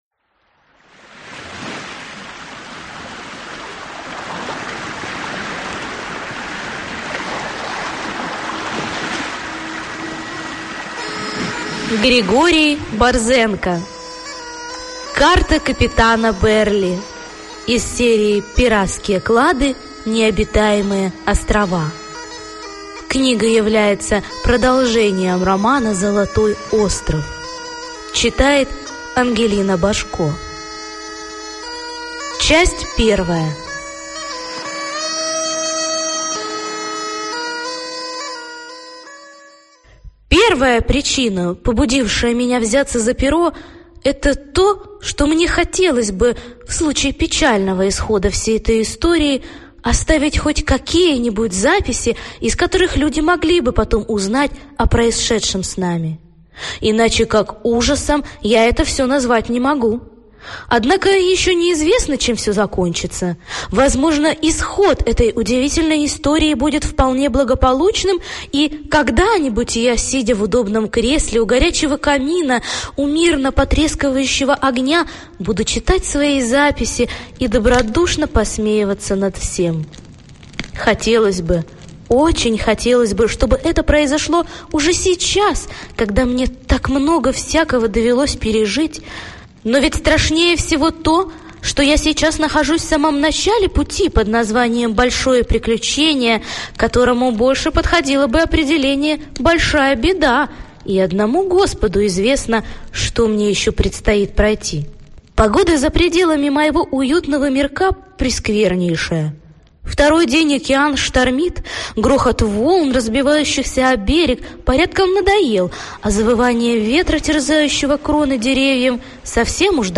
Аудиокнига Карта капитана Берли | Библиотека аудиокниг